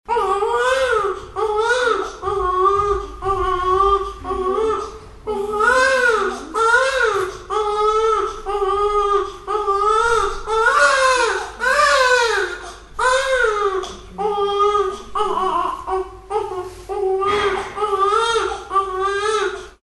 Звук плача малыша в больнице